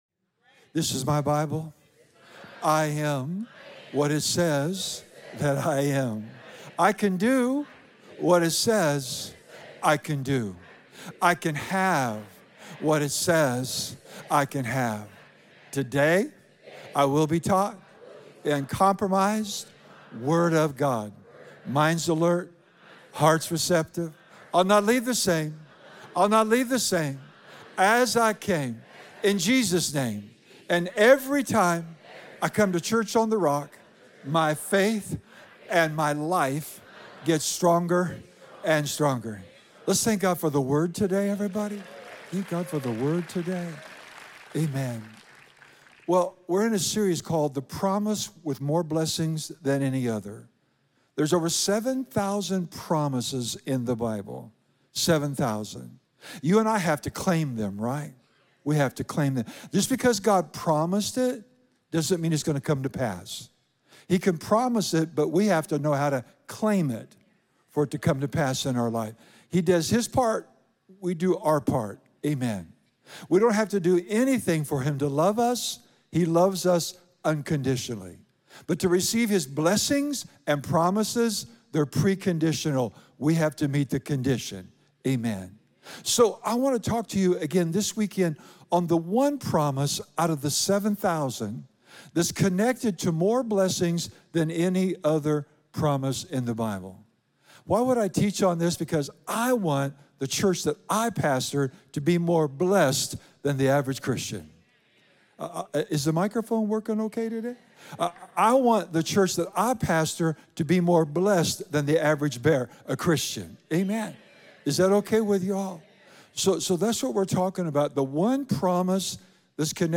In this powerful message from our series The Promise: With More Blessings Than Any Other, Pastor teaches on the fear of the Lord.